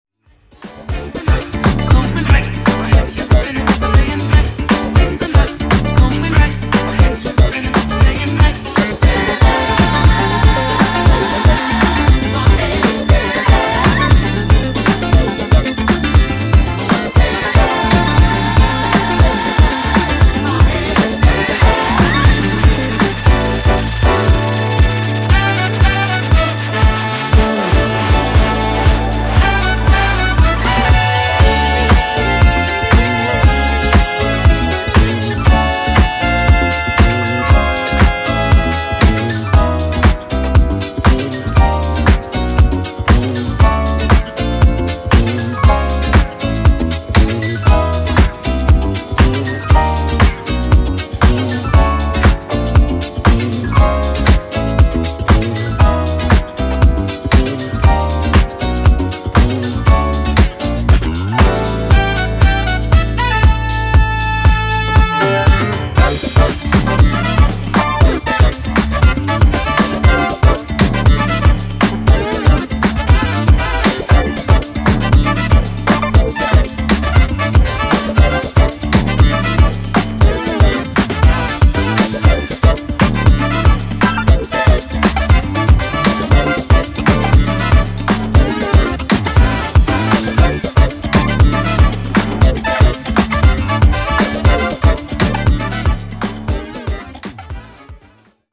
jazz disco
is a slower, more percussive jam